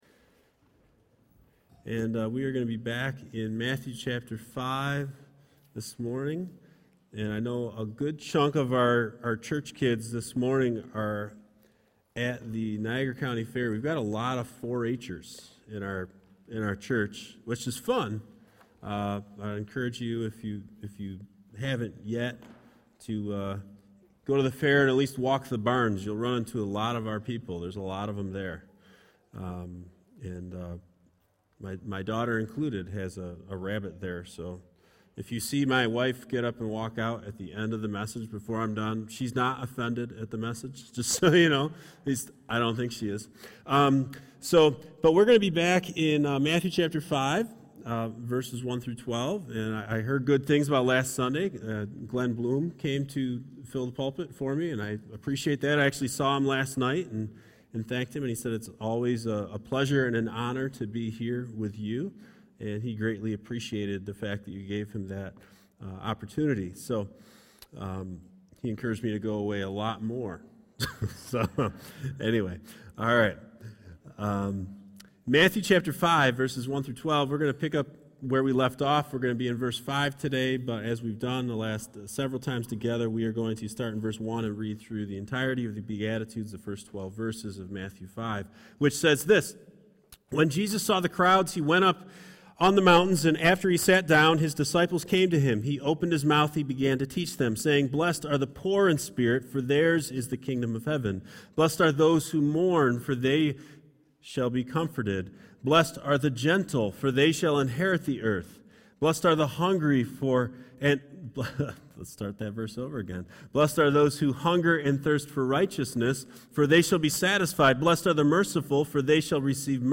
HBC-Sermon-Audio-4-Aug-2019.mp3